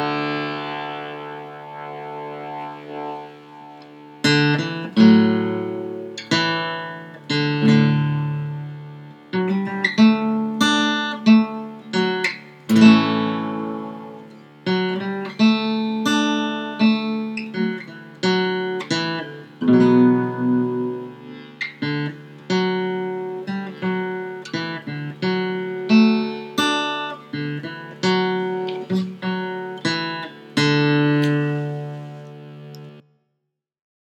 dear-old-albion-melody.m4a